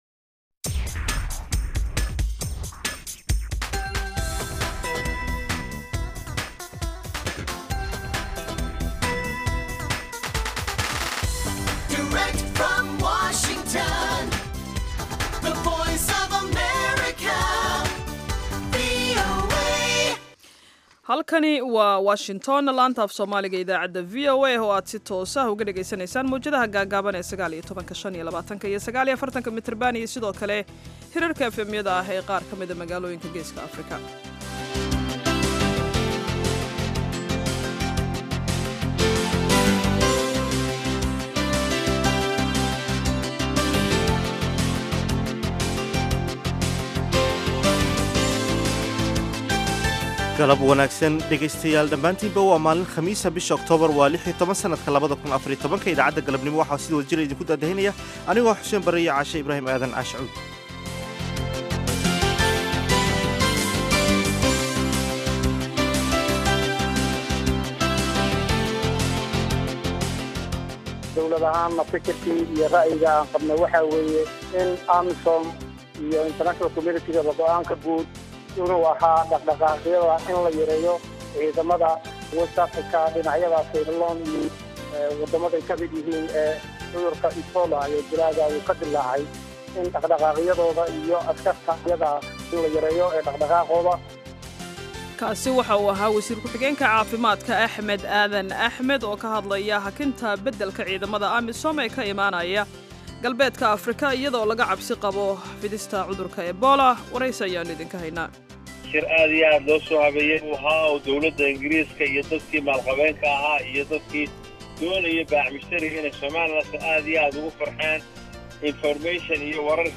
Idaacadda Galabnimo waxaad ku maqashaan wararka ugu danbeeya ee caalamka, barnaamijyo, ciyaaro, wareysiyo iyo waliba heeso.